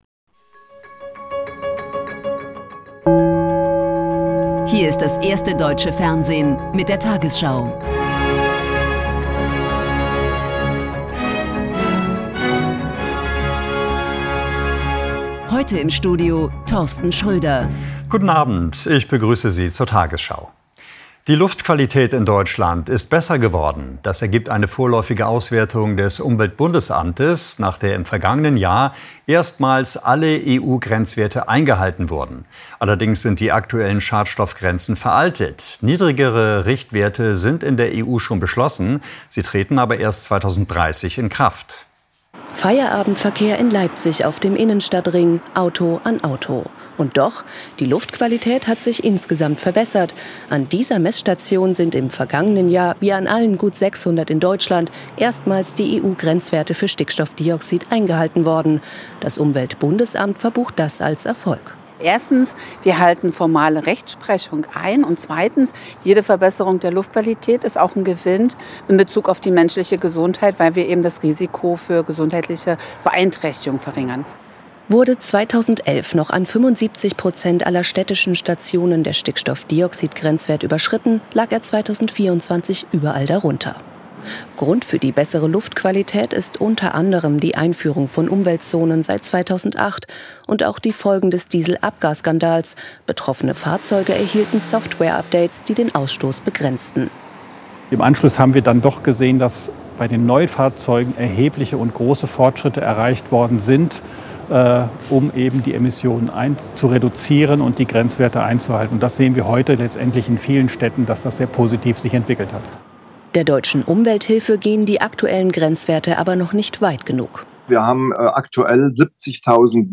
Die 20 Uhr Nachrichten von heute zum Nachhören. Hier findet ihr immer die aktuellsten und wichtigsten News.